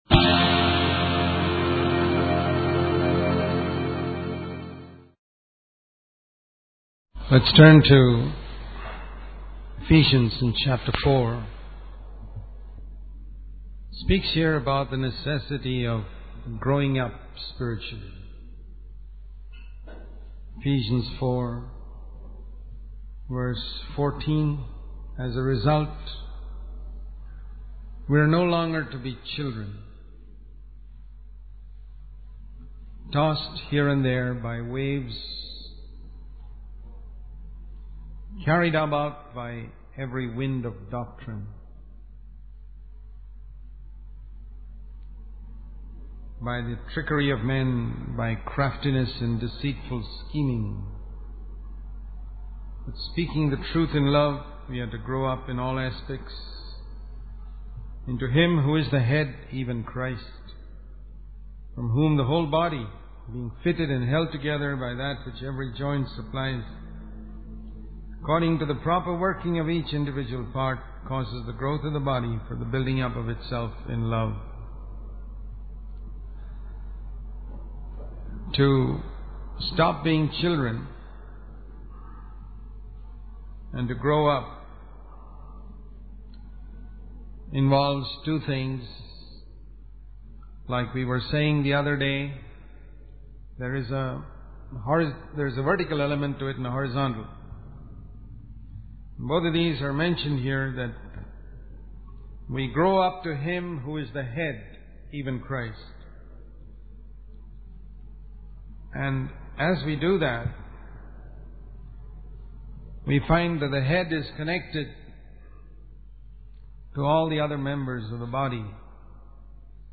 In this sermon, the speaker emphasizes the importance of not comparing oneself to others, but rather comparing oneself to what they could have been with the resources of God's grace. The speaker references Ecclesiastes 4:9, which states that two are better than one and can accomplish more together. The sermon also discusses the need for spiritual growth and maturity, as mentioned in Ephesians 4:14.